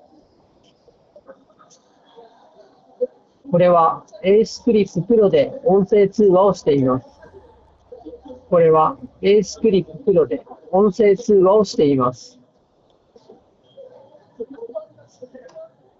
スピーカーから雑踏音をそこそこ大きなボリュームで流しながらマイクで収録した音声がこちら。
完全に雑音を除去しているわけではないですが、これくらいなら通話相手が聞き取りにくいことはなさそうです。
マイク音質も悪くはないので、仕事でのちょっとした打ち合わせ程度であれば使えると思います。
aceclip-pro-voice.m4a